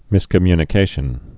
(mĭskə-mynĭ-kāshən)